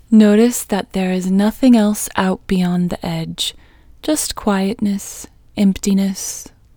OUT Technique Female English 7